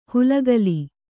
♪ hulagali